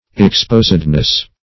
Search Result for " exposedness" : The Collaborative International Dictionary of English v.0.48: Exposedness \Ex*pos"ed*ness\, n. The state of being exposed, laid open, or unprotected; as, an exposedness to sin or temptation.
exposedness.mp3